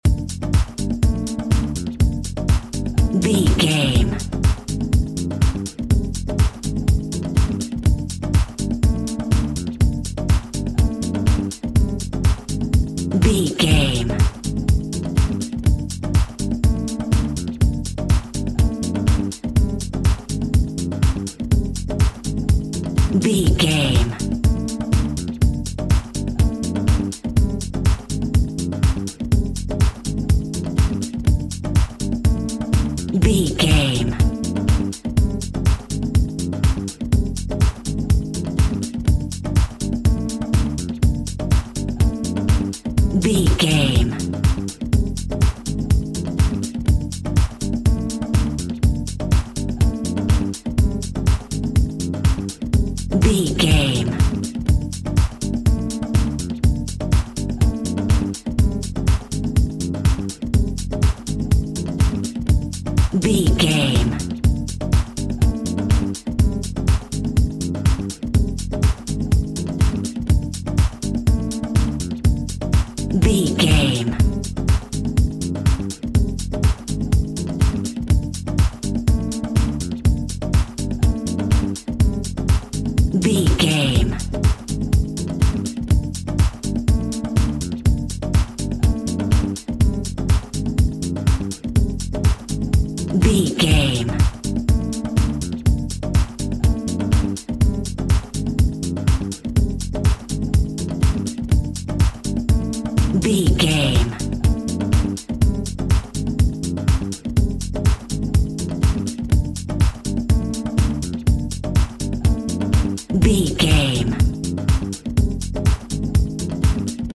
On Hold Music Cue.
Fast paced
In-crescendo
Aeolian/Minor
funky
groovy
uplifting
driving
energetic
bass guitar
electric guitar
drums
electric piano
Lounge
chill out
laid back
nu jazz
downtempo
synth drums
synth leads
synth bass